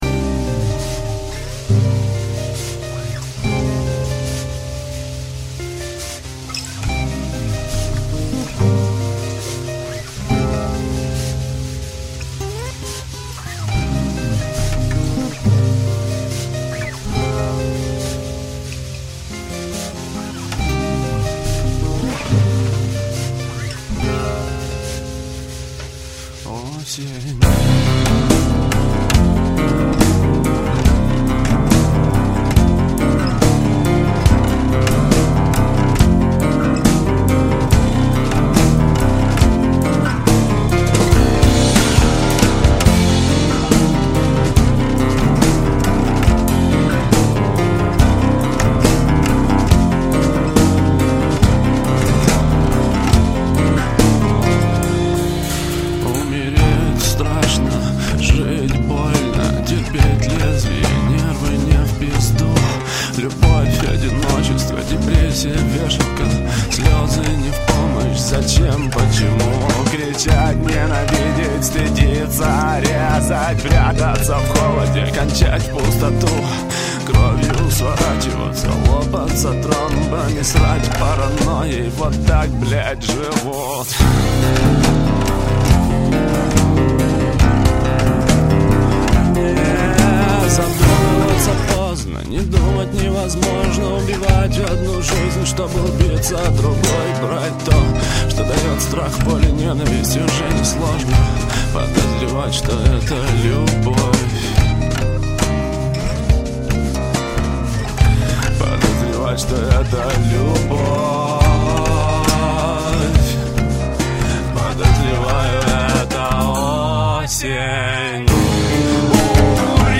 (Акустика) Бек вокал